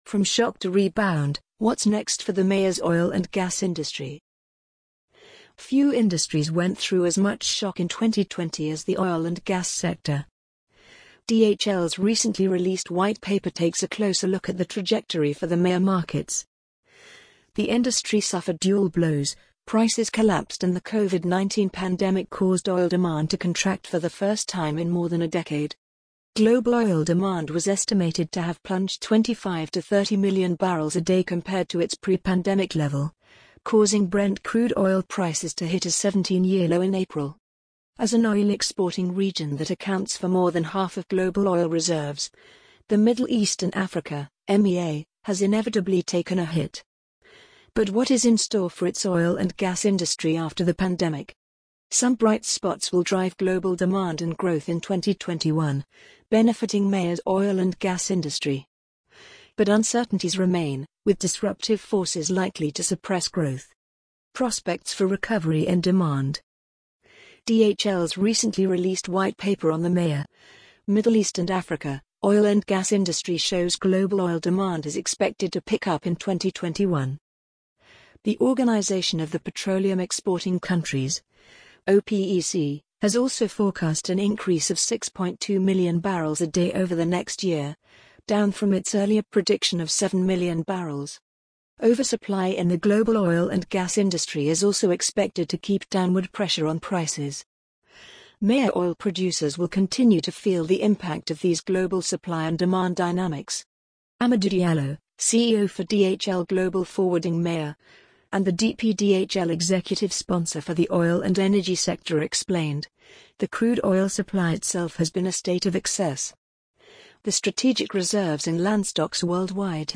amazon_polly_9858.mp3